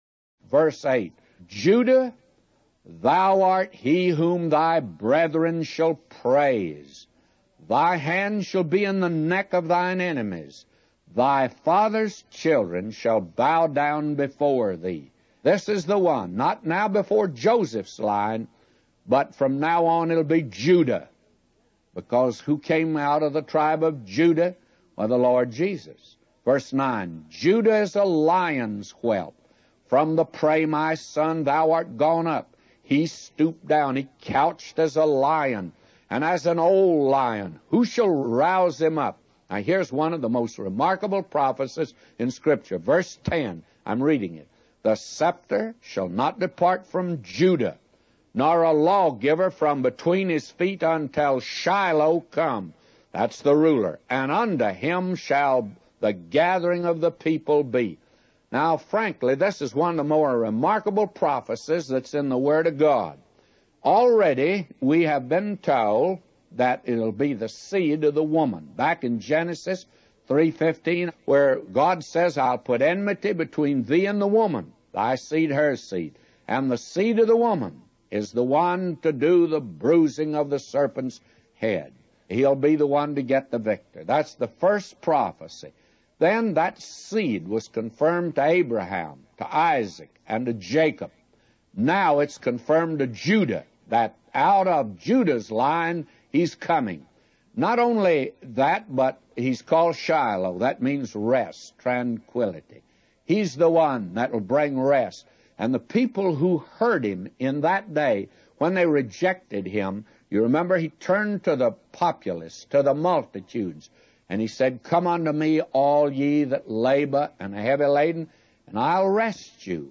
A Commentary By J Vernon MCgee For Genesis 49:8-999